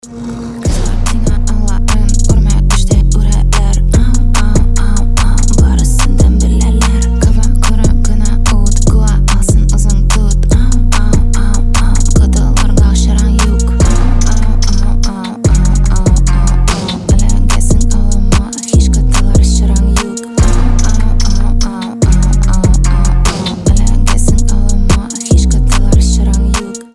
• Качество: 320, Stereo
женский вокал
восточные мотивы
атмосферные
чувственные
рык